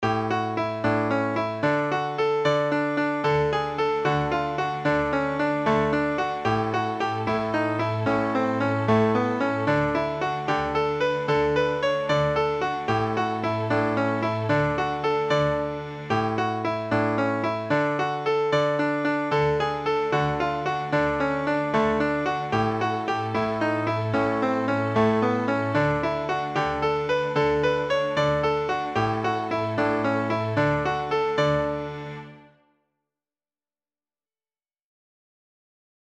String Practice Pieces